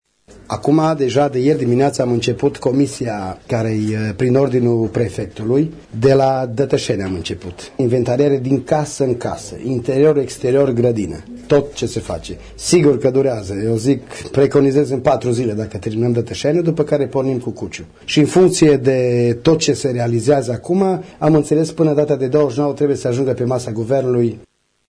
Primarul Ilie Şuta: